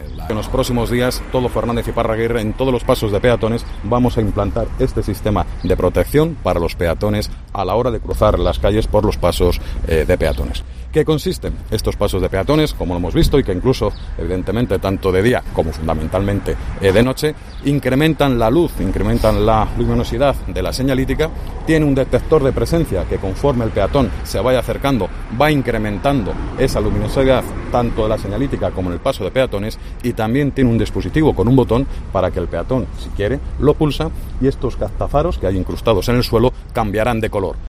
El vicealcalde, Jaime Carnicero, señala que el proyecto de implementación de los Sistemas de Seguridad Vial tiene el objetivo primordial de incrementar la seguridad y la protección de los peatones, en puntos que por su estratégica situación y por ser zonas de un gran tránsito peatonal se ha considerado necesario mejorar.